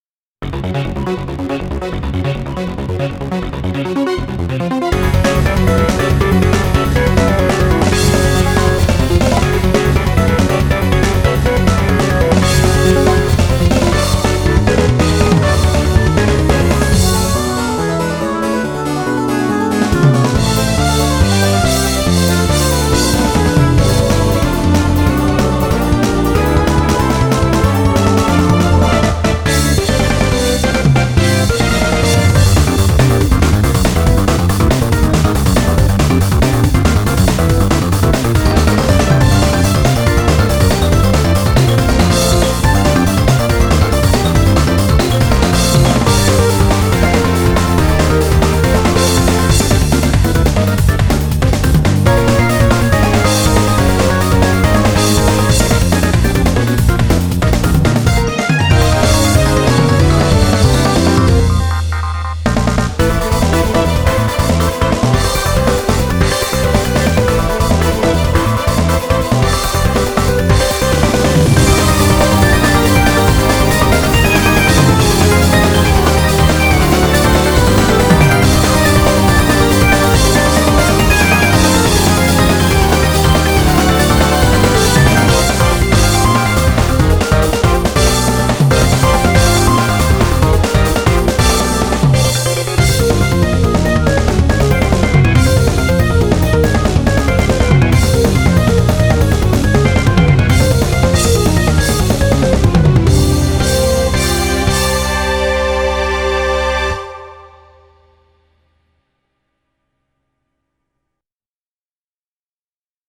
BPM280
Audio QualityPerfect (High Quality)
Genre: EPIC PROG.